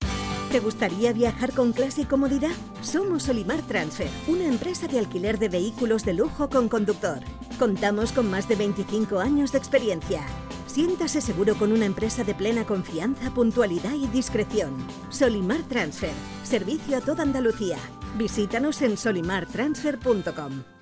PATROCINIO_GRUPO_SOLYMAR_CADENA_SER.mp3